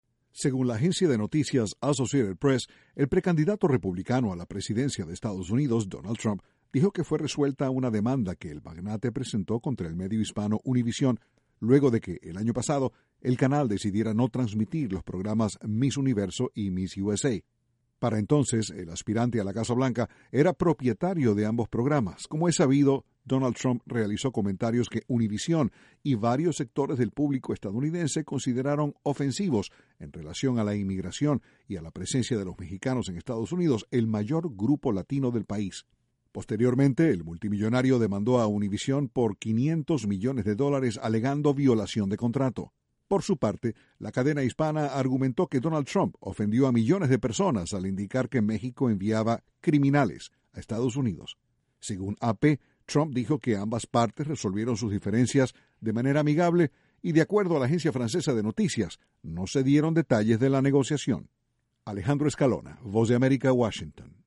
La cadena hispana Univisión y Donald Trump habrían resuelto sus diferencias legales. Desde la Voz de América, Washington